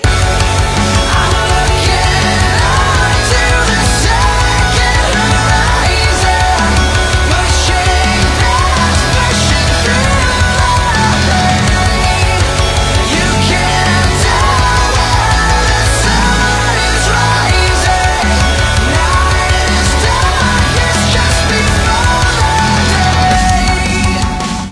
• Качество: 192, Stereo
под тяжелый, но мелодичный рок